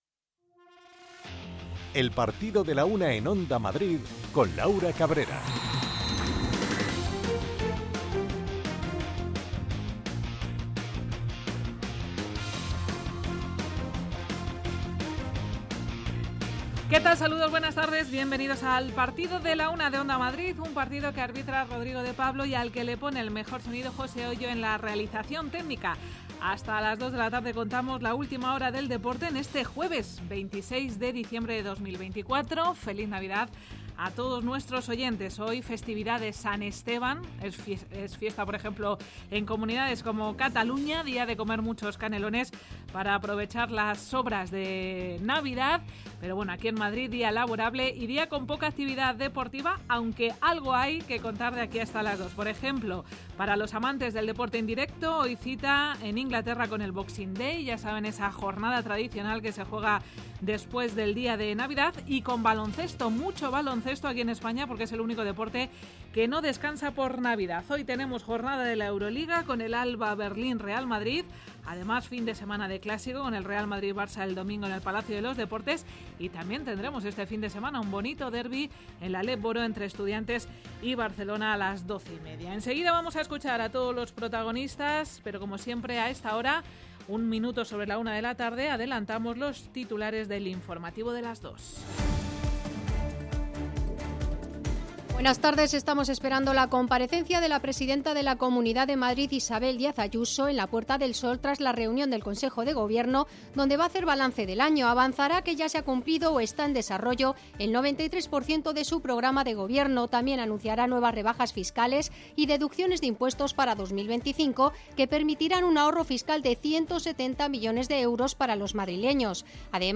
Es la referencia diaria de la actualidad deportiva local, regional, nacional e internacional. El rigor en la información y el análisis medido de los contenidos, con entrevistas, reportajes, conexiones en directo y el repaso a la agenda polideportiva de cada día, son la esencia de este programa.